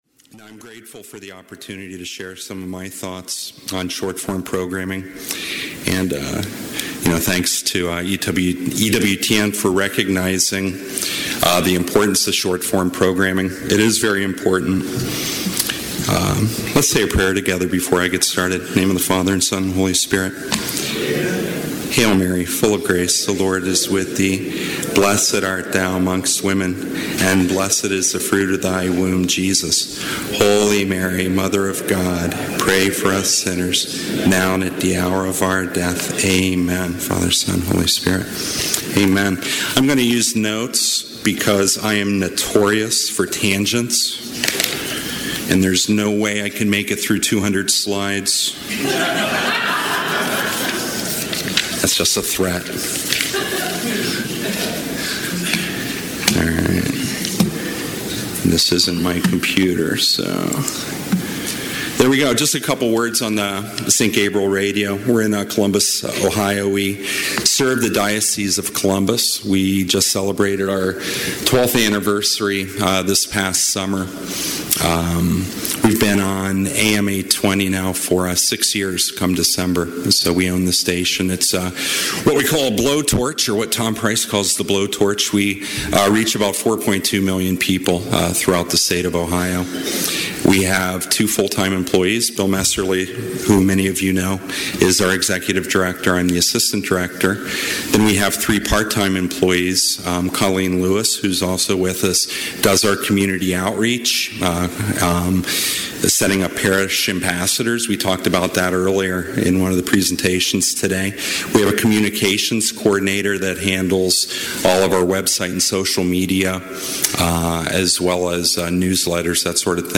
18th Annual EWTN Catholic Radio Conference